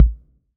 6 BD 1.wav